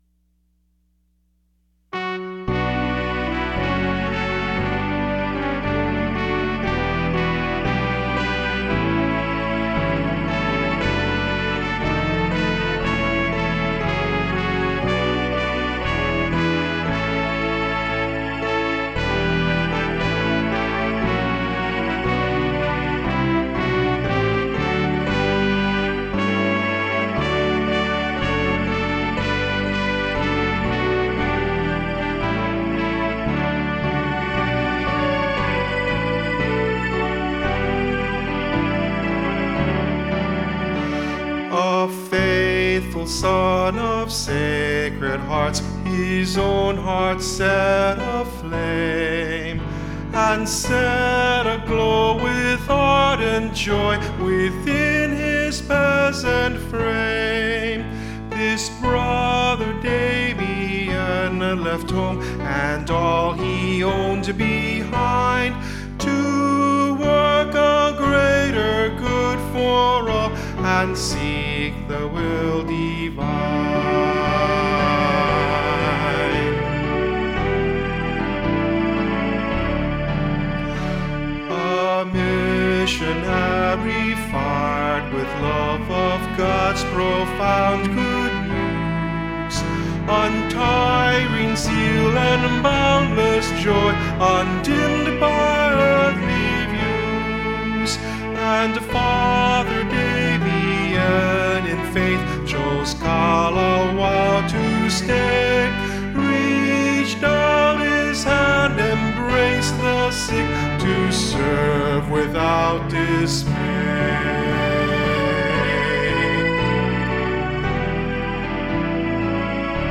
Voice | Downloadable melody